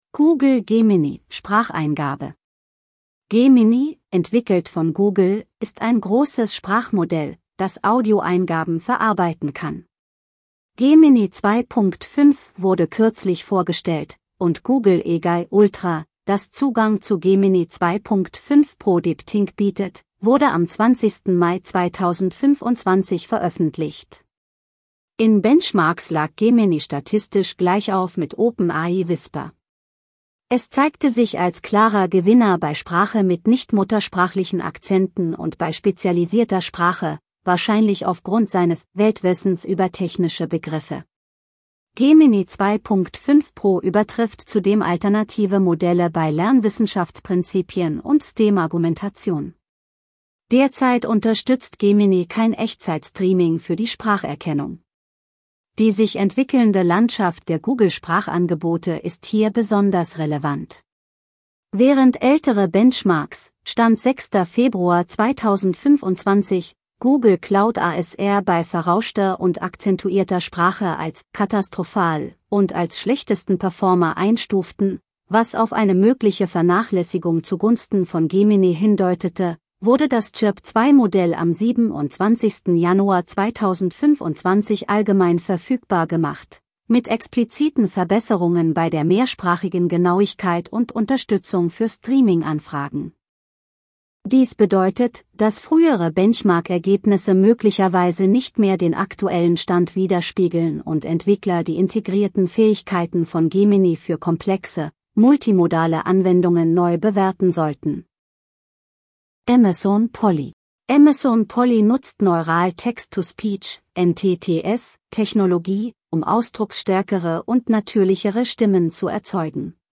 Amazon Polly nutzt Neural Text-to-Speech (NTTS)-Technologie, um ausdrucksstärkere und natürlichere Stimmen zu erzeugen.